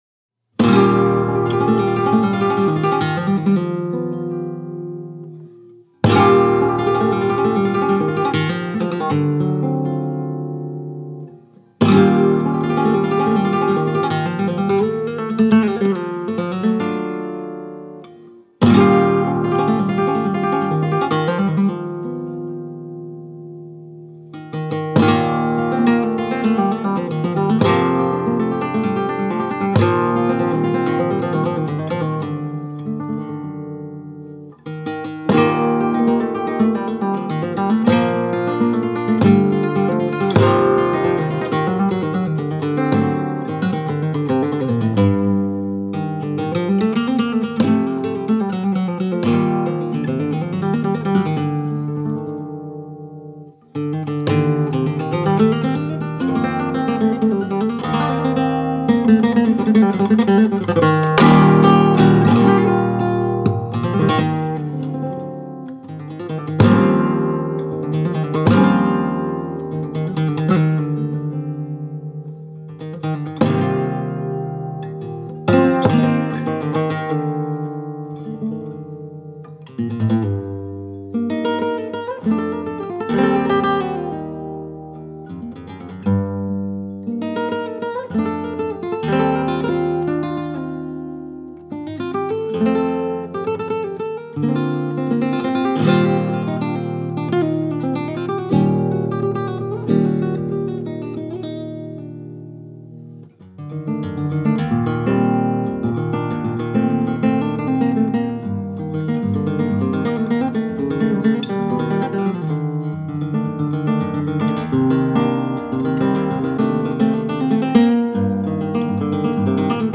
(rondeña)
composition et guitare